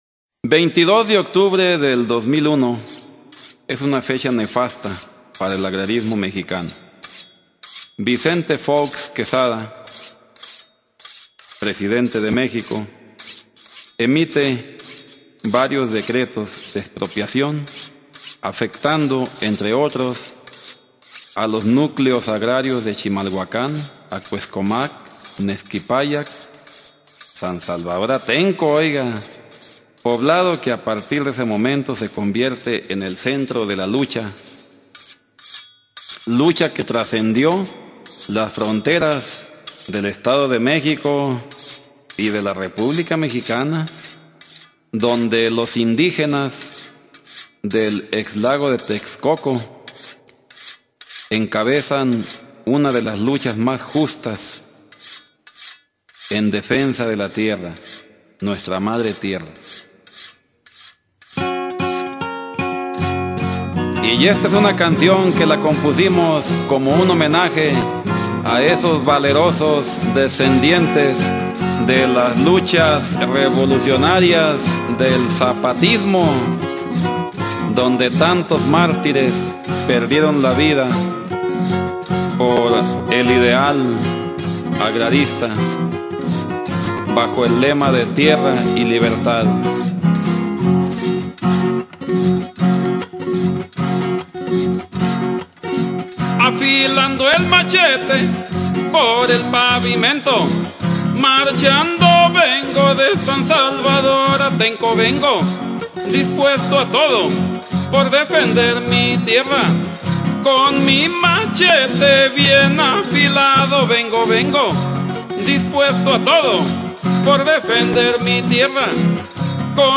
song >